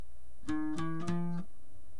para bajosexto!!!!!